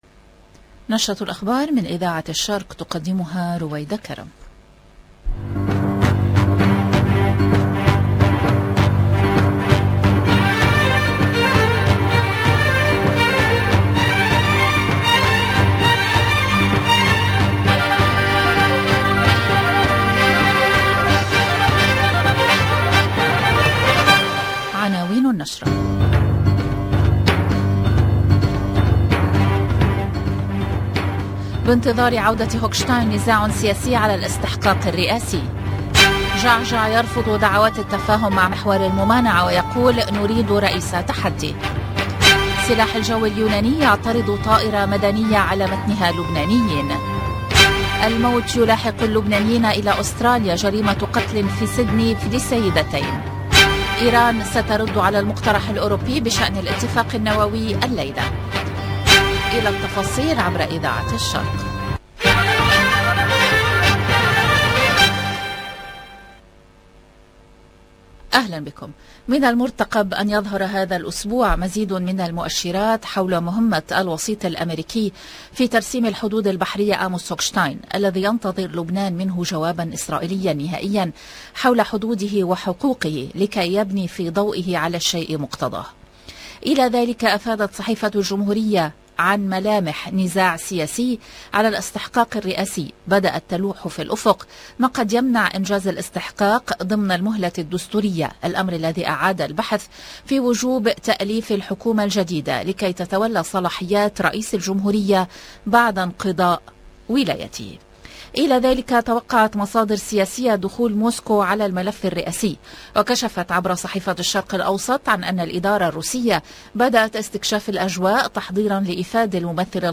EDITION DU JOURNAL DU SOIR DU LIBAN DU 15/8/2022